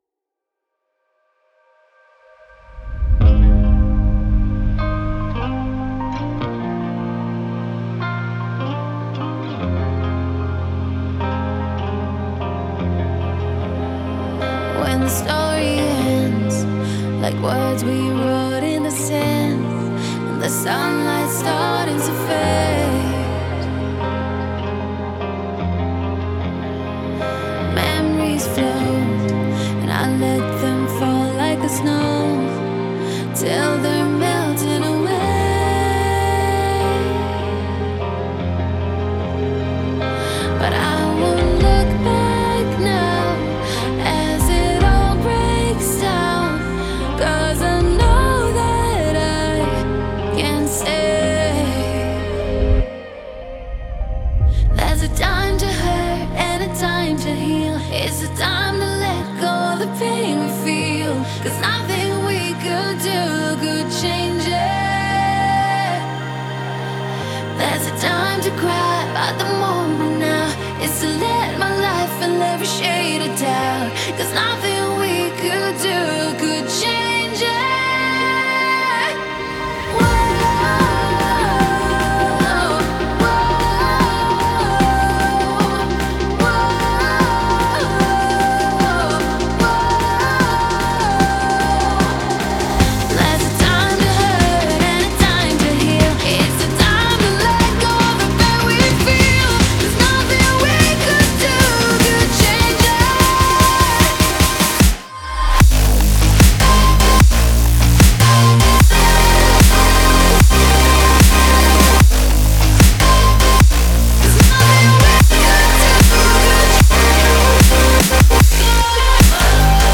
это эмоциональная композиция в жанре электронной музыки